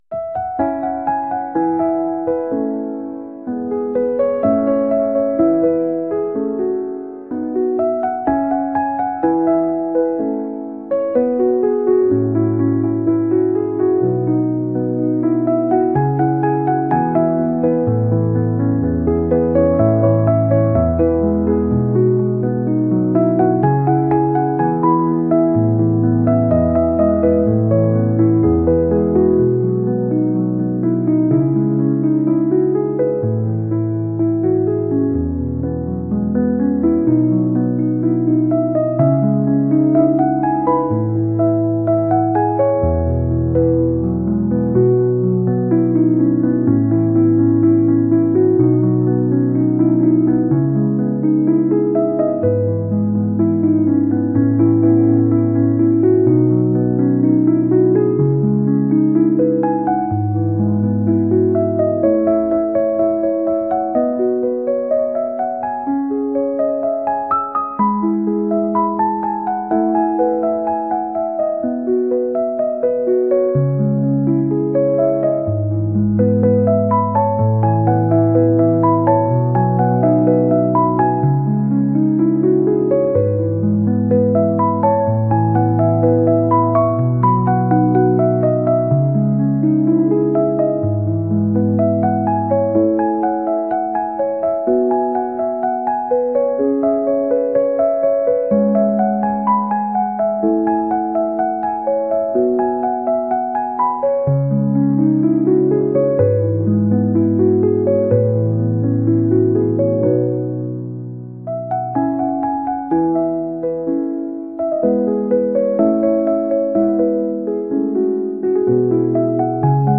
Satisfying coloring with soft markers